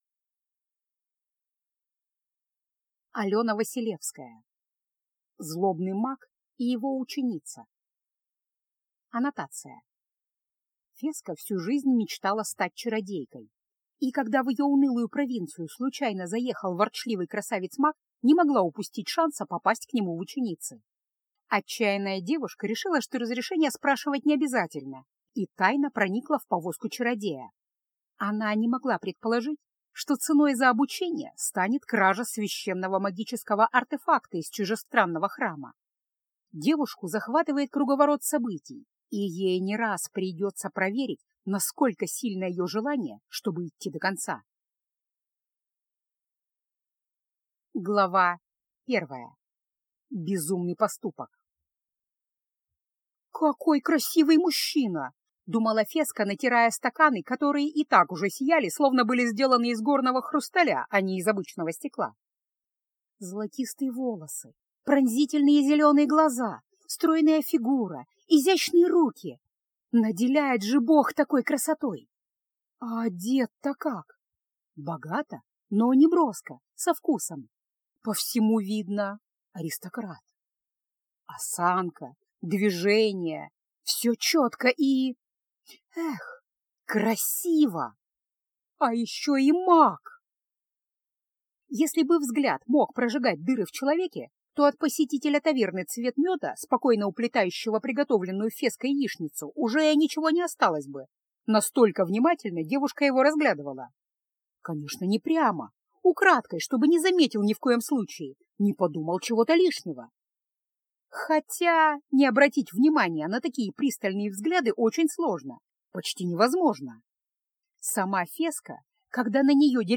Аудиокнига Злобный маг и его ученица | Библиотека аудиокниг